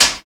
81 METAL.wav